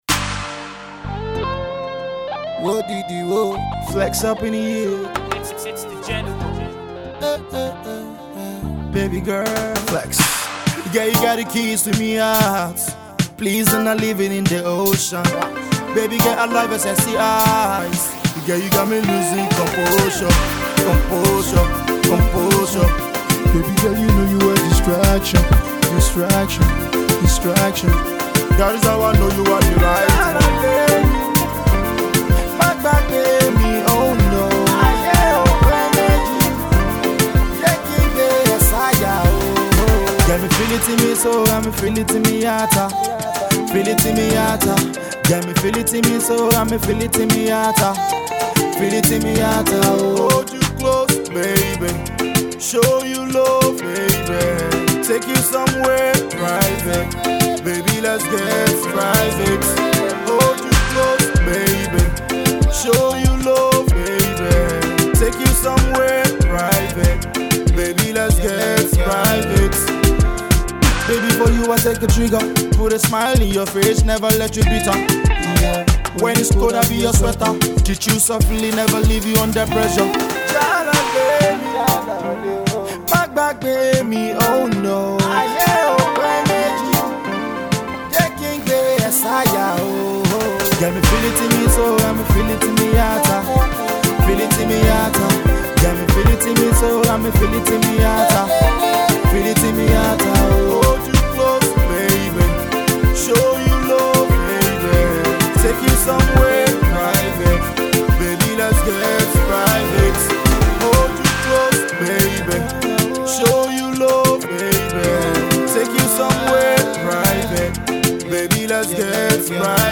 Afro-pop
sonorous, melodious and soothing love piece